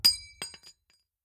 nut_impact_01.ogg